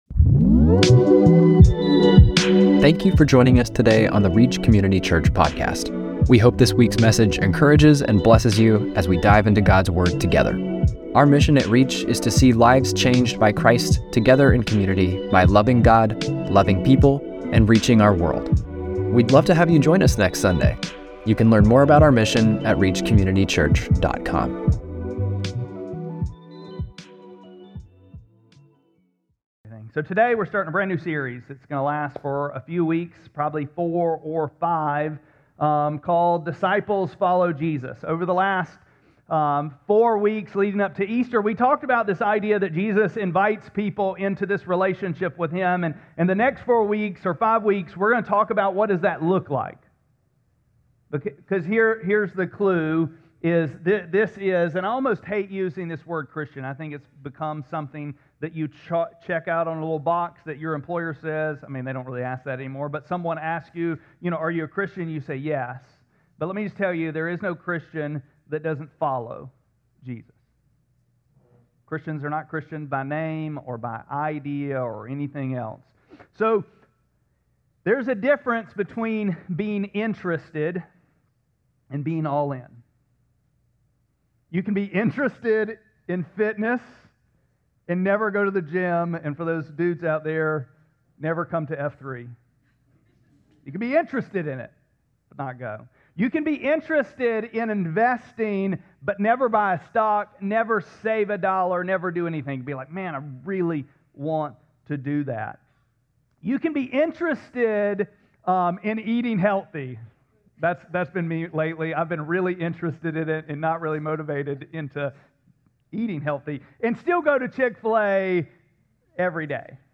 4-12-26-Sermon-.mp3